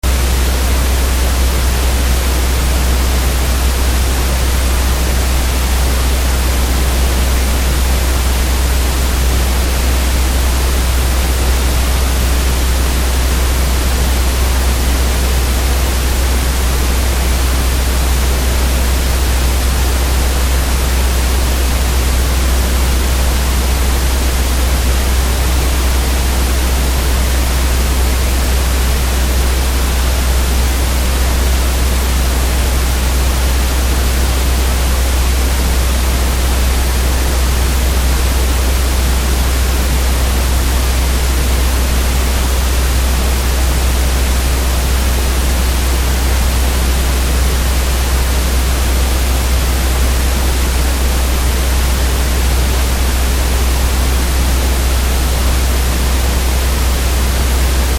Estos sonidos tienen un mismo LAeq.
El primero de todos es ruido rosa con dicho LAeq.